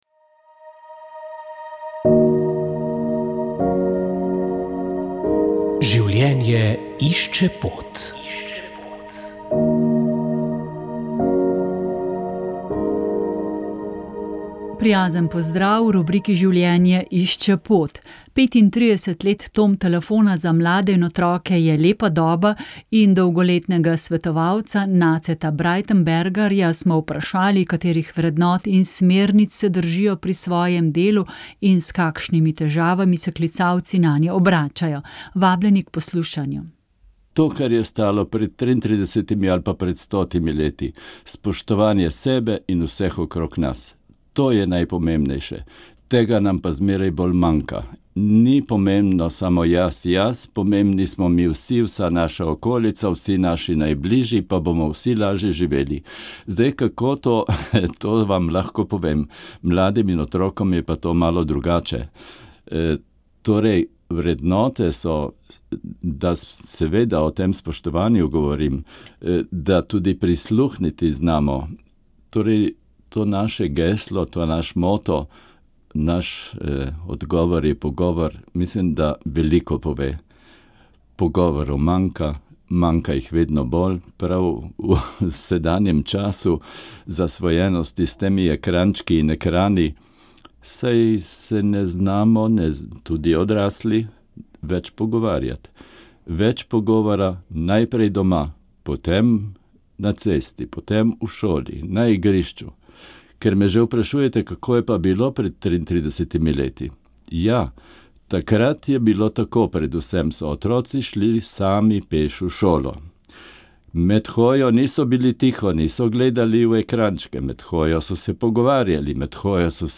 Molili so člani Karitas iz župnij Preska in Smlednik.
Rožni venec